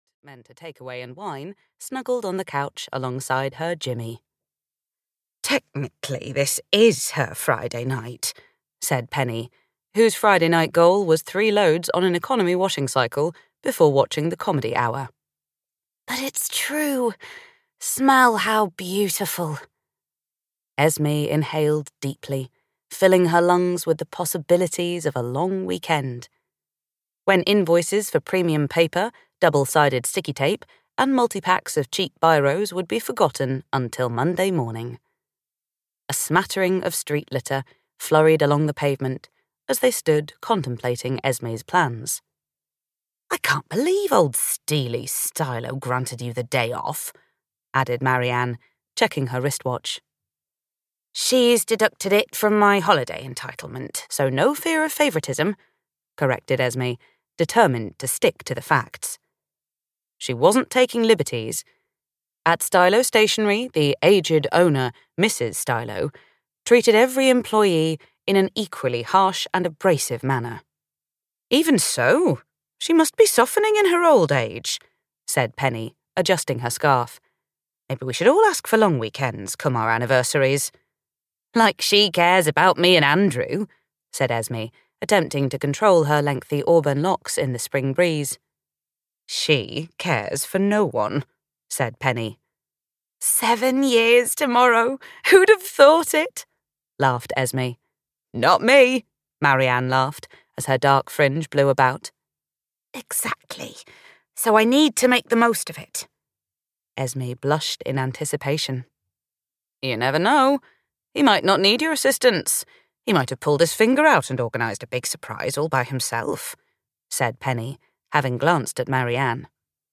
The Single Girl's Calendar (EN) audiokniha
Ukázka z knihy